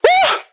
BWB VAULT VOX (Edm Chnat).wav